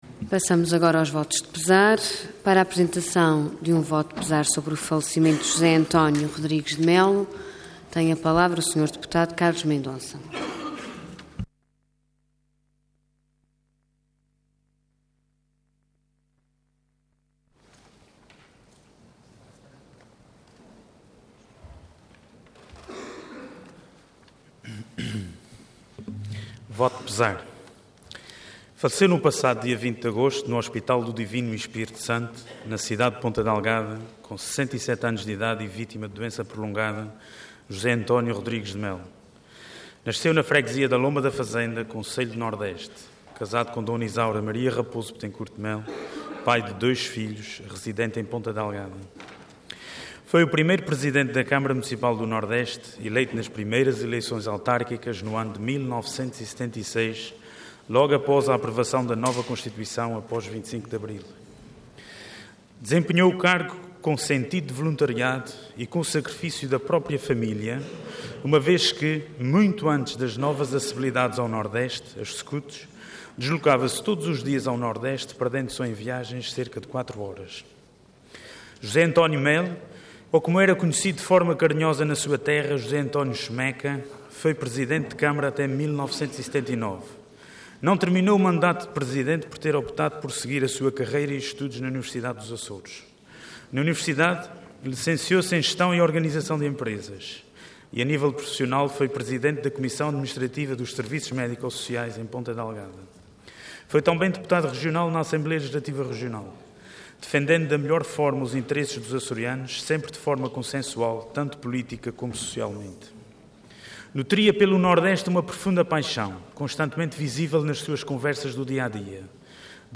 Intervenção Voto de Pesar Orador Carlos Mendonça Cargo Deputado Entidade PS